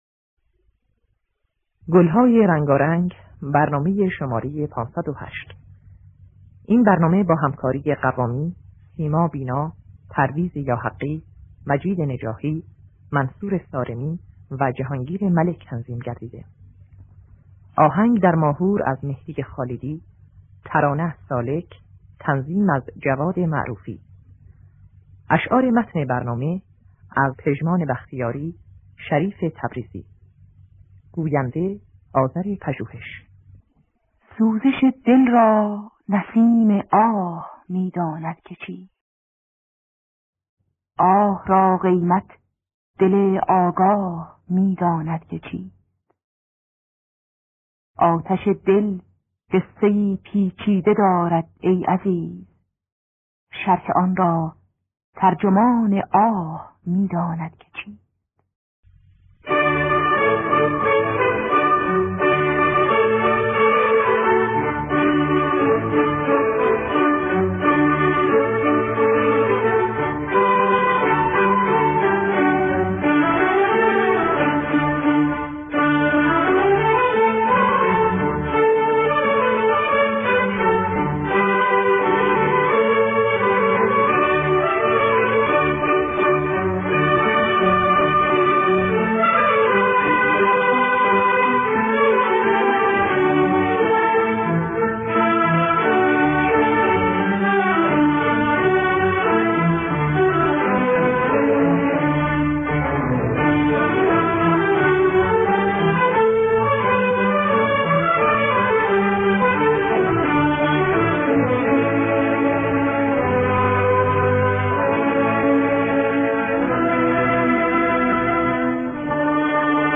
دستگاه ماهور